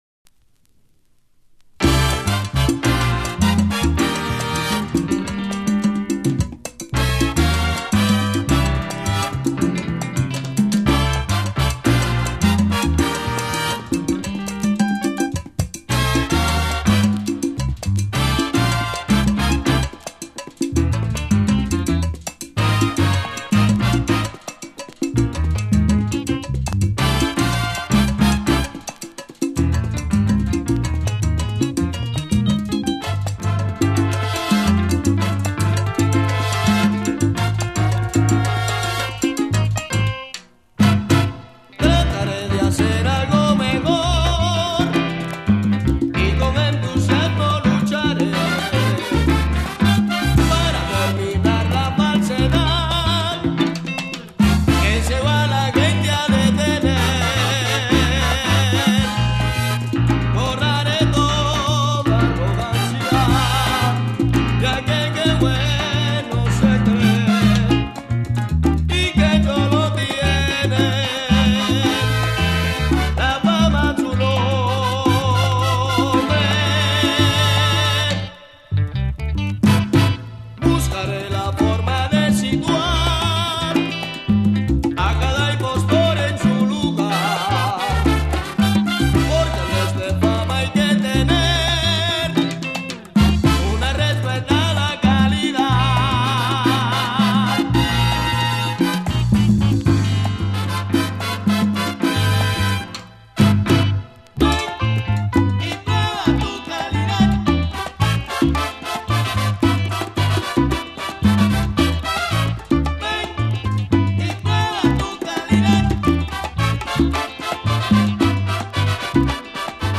SALSA